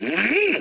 Mewtwo_voice_sample_SSBM.oga.mp3